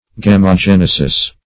Search Result for " gamogenesis" : The Collaborative International Dictionary of English v.0.48: Gamogenesis \Gam`o*gen"e*sis\, n. [Gr.